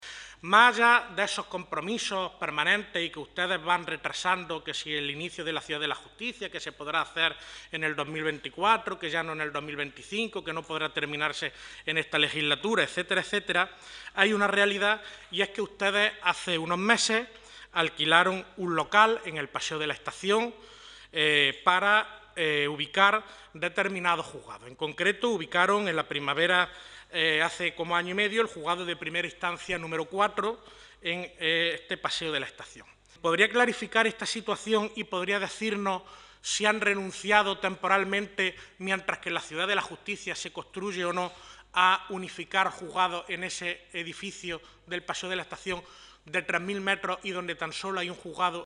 En Comisión
Cortes de sonido